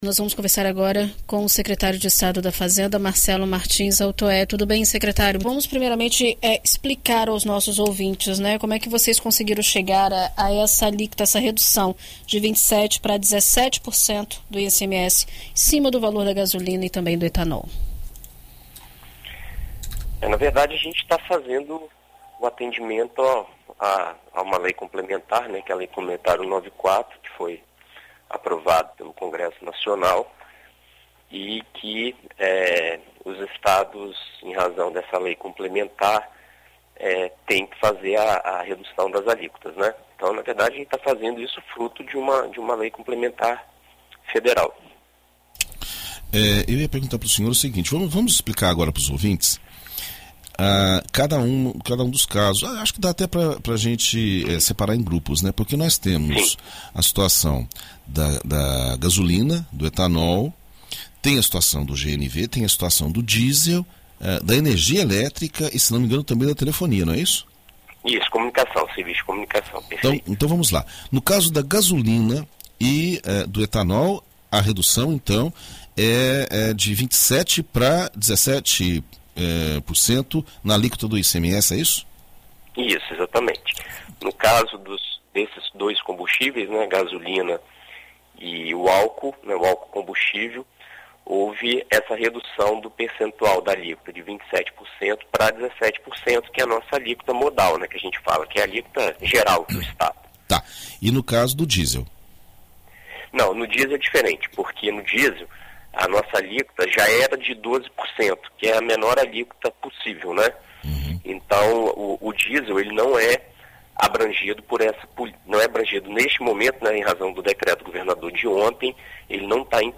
Em entrevista à BandNews FM Espírito Santo nesta quarta-feira (29), o secretário de Estado da Fazenda, Marcelo Martins Altoé, fala sobre a alteração e como o governo pretende lidar com a queda nos repasses para áreas importantes, como a educação e a saúde, que possuem 25% e 12%, respectivamente, dos recursos do ICMS.